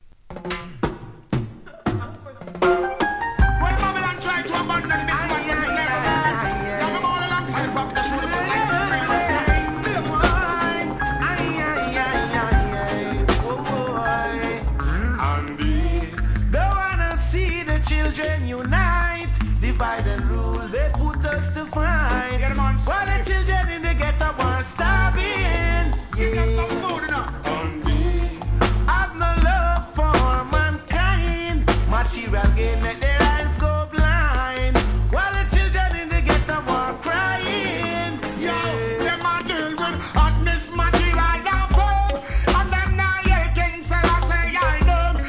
REGGAE
リメイクRHYTHM!